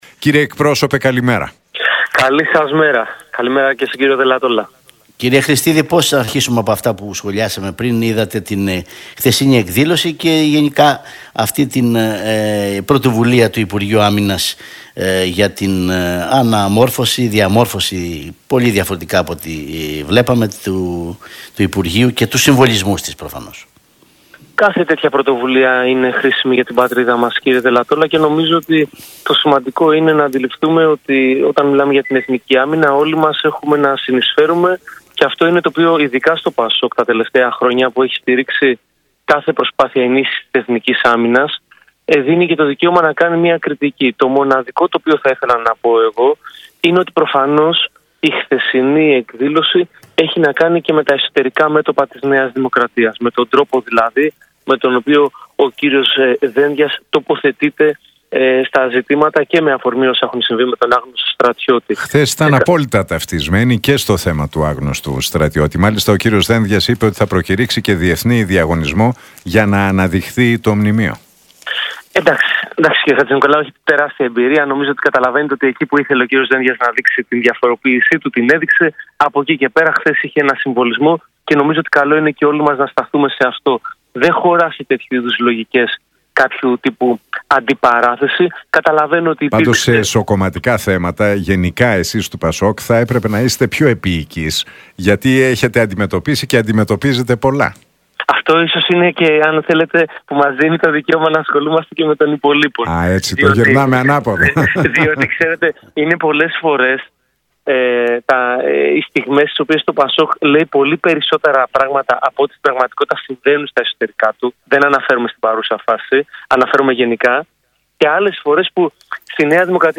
από τη συχνότητα του Realfm 97,8.